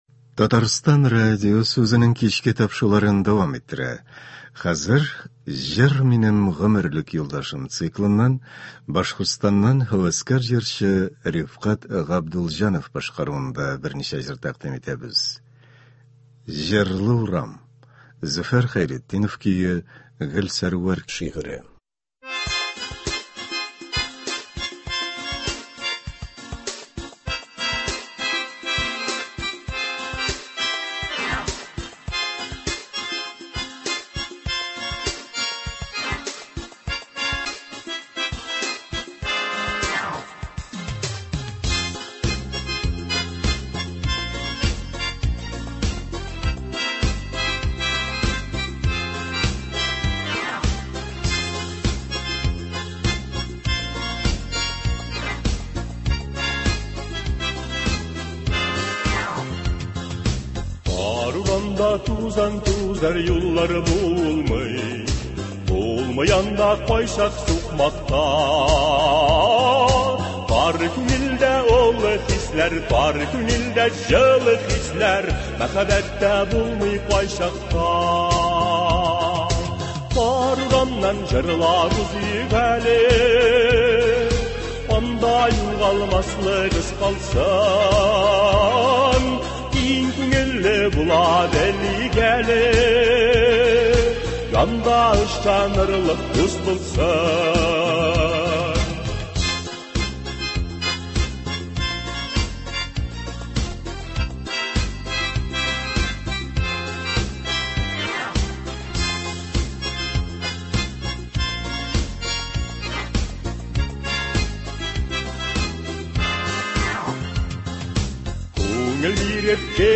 Концерт. 10 февраль.